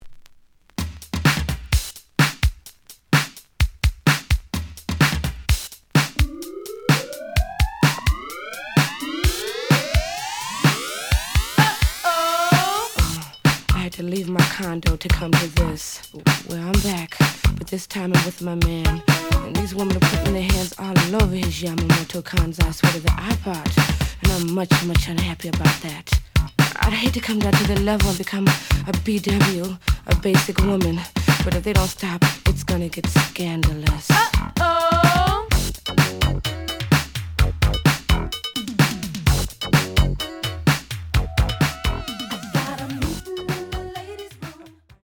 The audio sample is recorded from the actual item.
●Genre: Funk, 80's / 90's Funk
Slight edge warp.